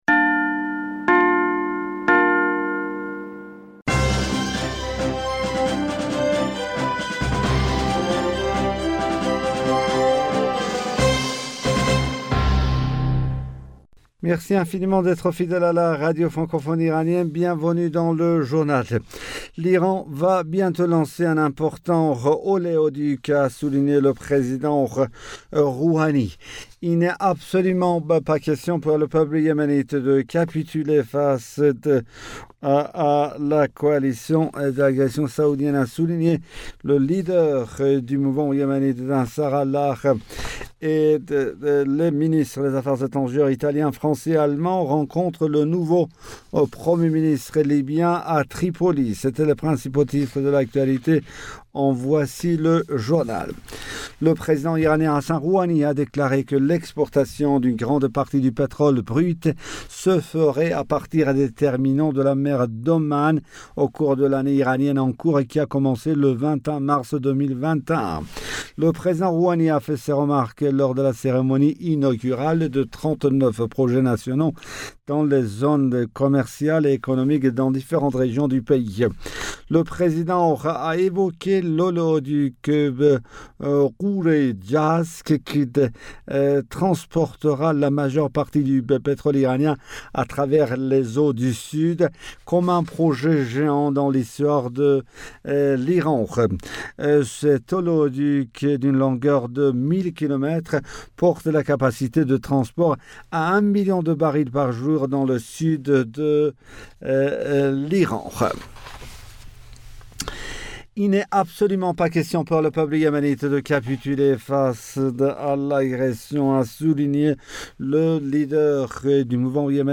Bulletin d'informationd du 26 Mars 2021